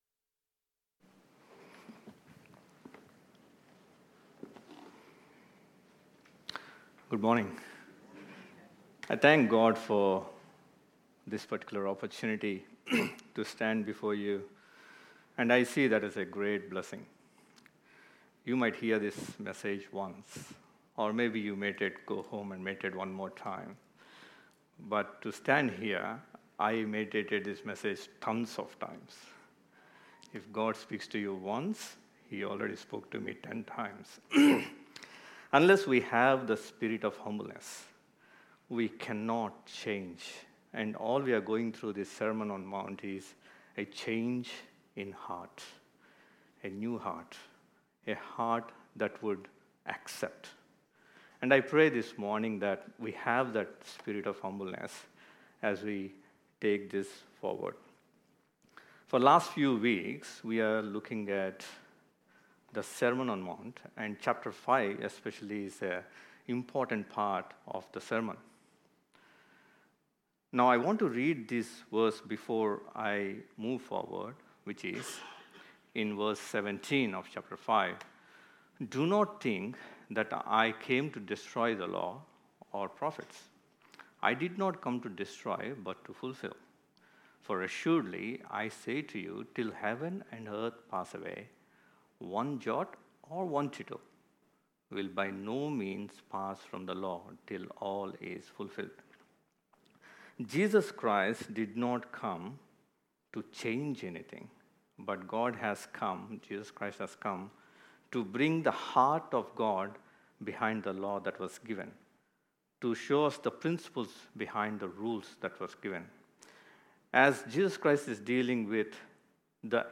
KPCC Sermons | Kings Park Community Church